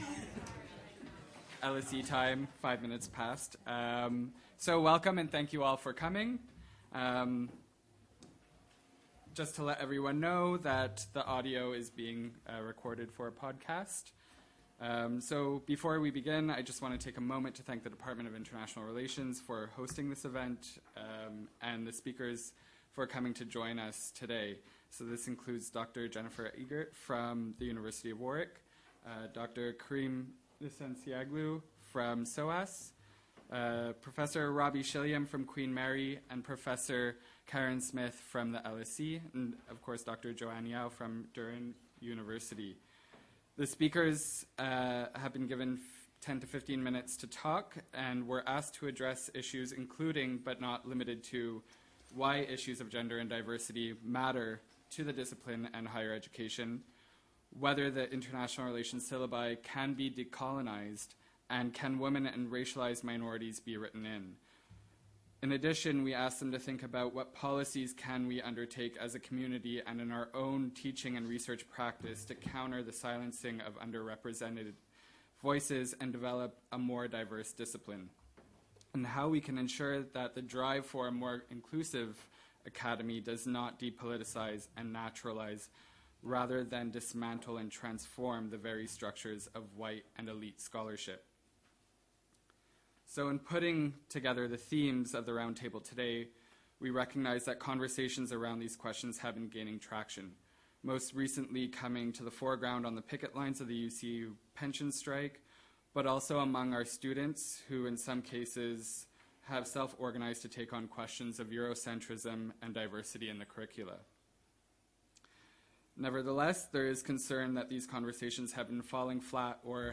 Listen to or download podcasts from our earlier public events at the Department of International Relations at LSE